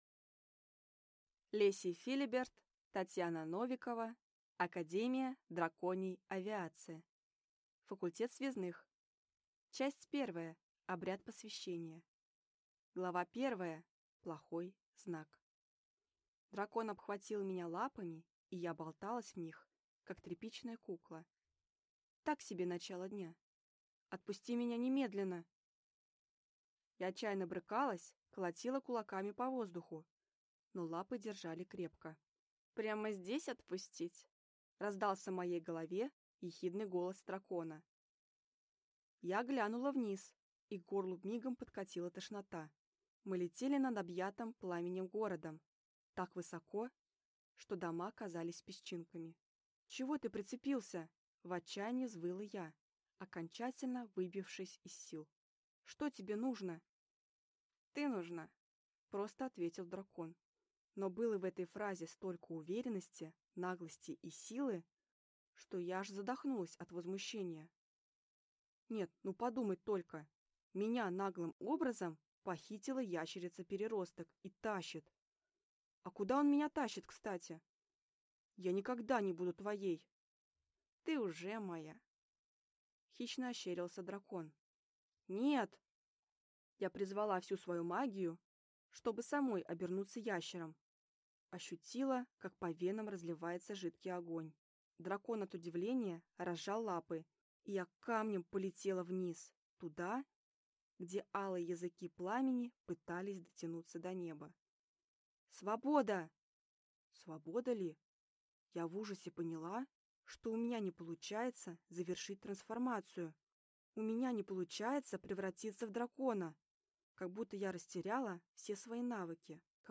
Аудиокнига Академия Драконьей Авиации. Факультет Связных | Библиотека аудиокниг